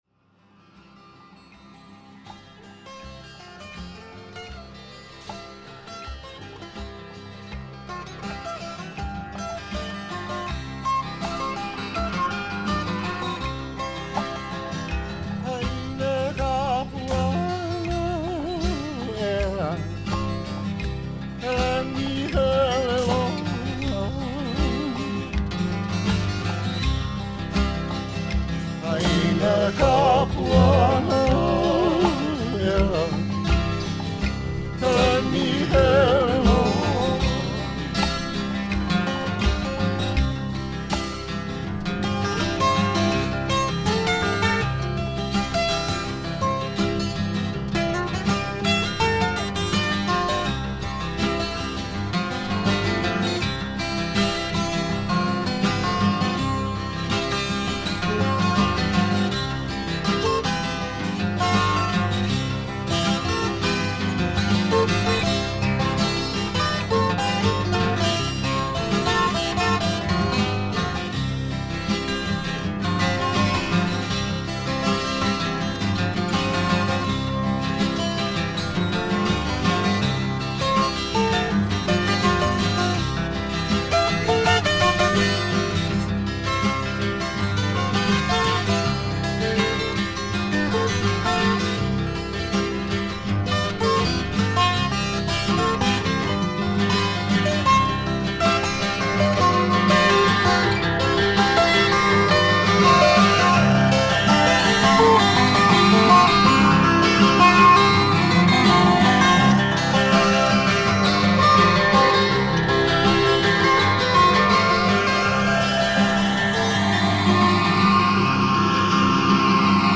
Avant-Garde Folk International Pop Reggae/Dub